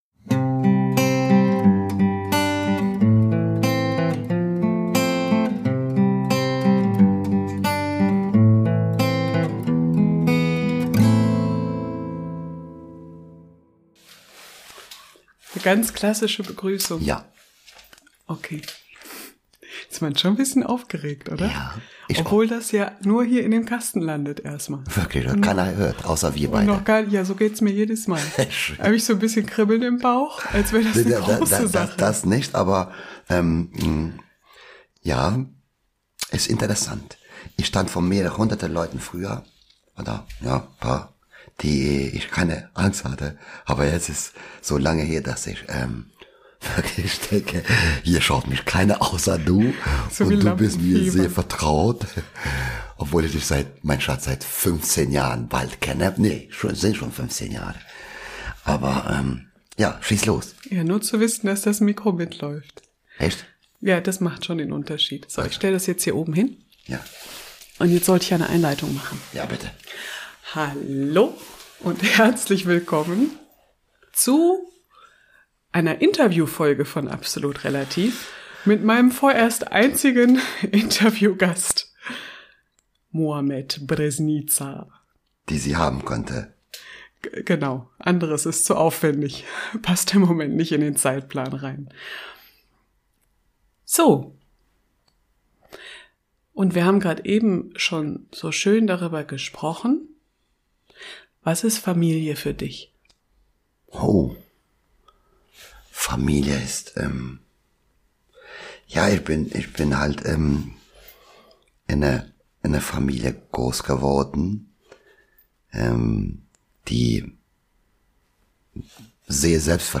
So gesehen ist jede Beziehung bi-kulturell. In dieser Folge - Folge 22 - unterhalten wir uns über dies und das: über Heimat und kulturelle Unterschiede, Familie, übers Kindsein und Großwerden, über Geduld und Authentizität.